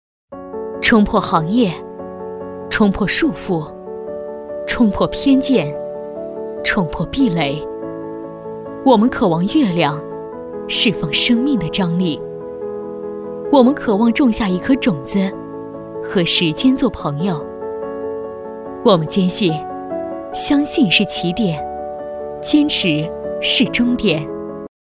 女285-独白-【冲破-坚毅有力】
女285-明亮柔和 大气激昂